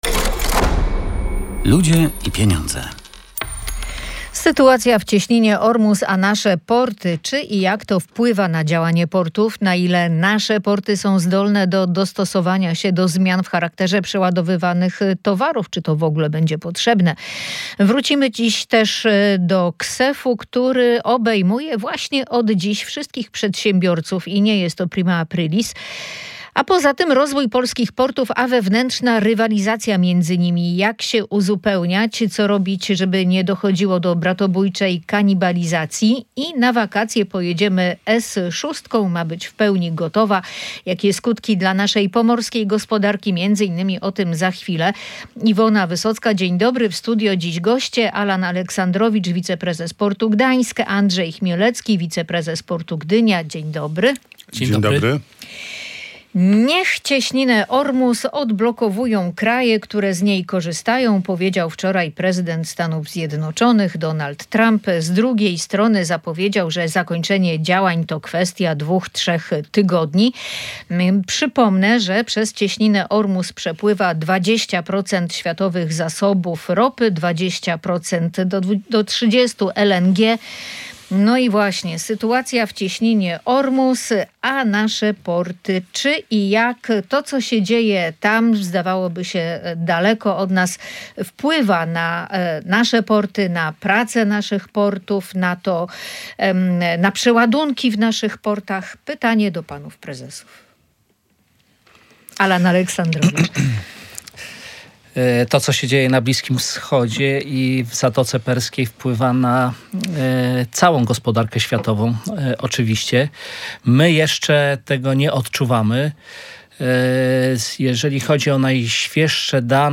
Przedłużanie się wojny w Zatoce Perskiej będzie wpływało na ceny paliw, ale nie spowoduje braku tego surowca w Polsce – zapewniali goście audycji „Ludzie i Pieniądze”.